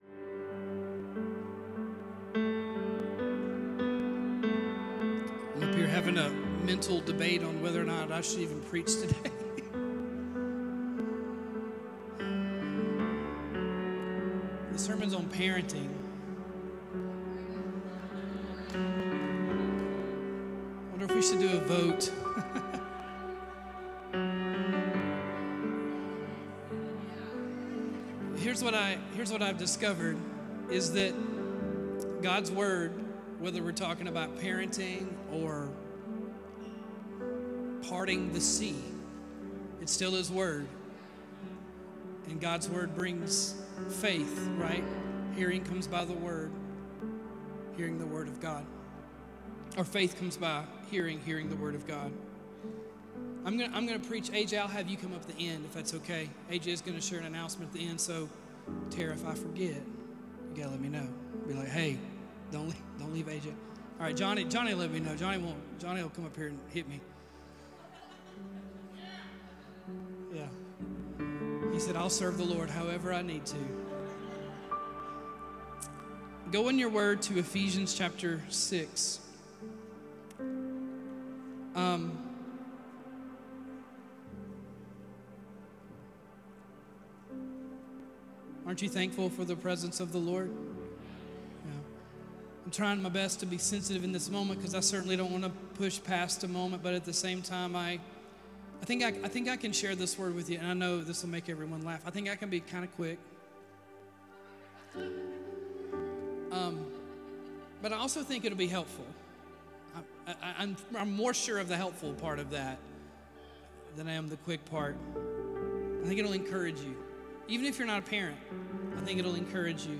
The Most Important Job You'll Ever Have | Family Matters | 08.03.25 | LifeHouse Church